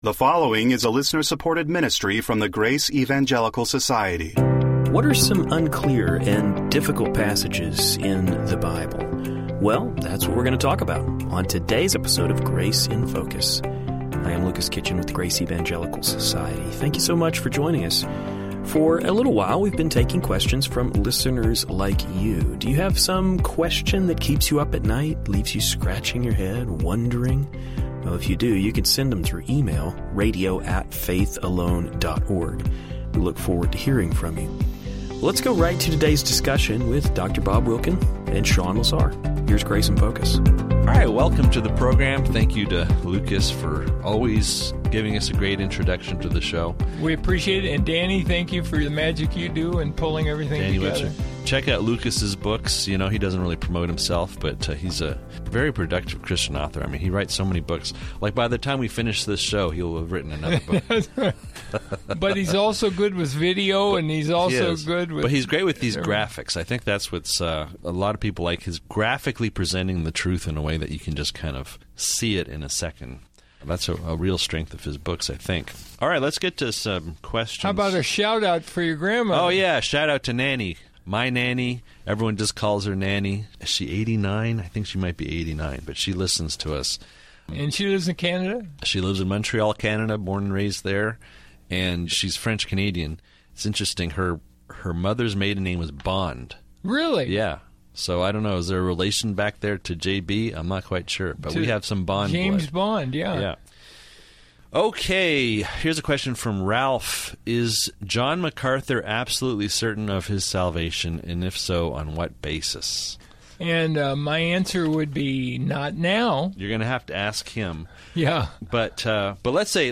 Today, we will hear the guys respond to a question regarding John MacArthur.